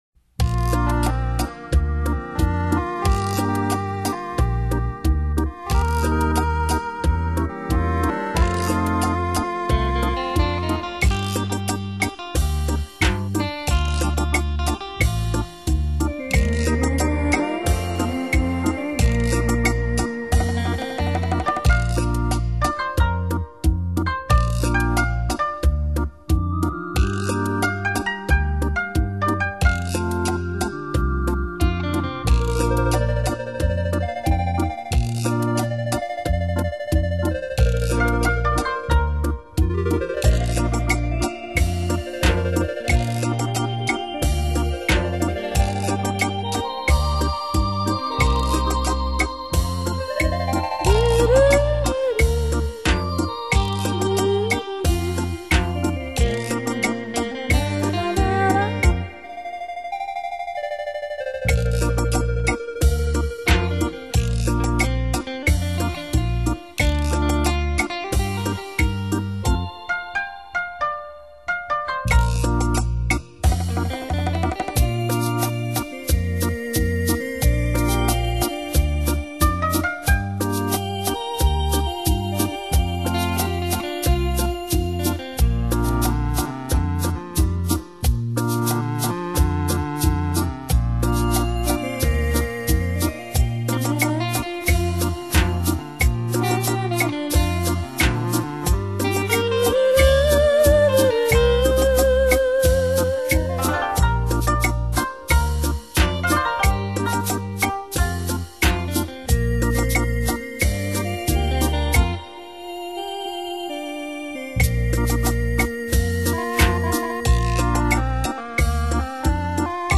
[2005-11-23]台湾“电脑鼓”《爱你入骨》 激动社区，陪你一起慢慢变老！